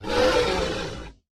Sound / Minecraft / mob / horse / zombie / angry.ogg
angry.ogg